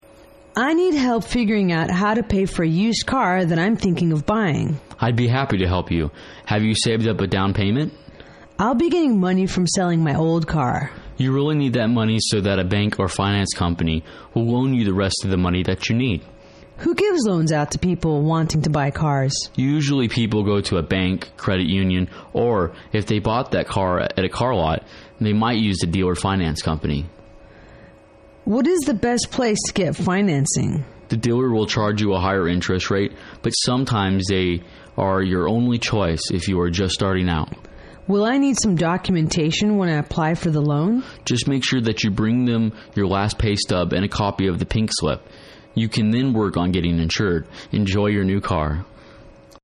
英语情景对话-How to Pay for the Used Car(3) 听力文件下载—在线英语听力室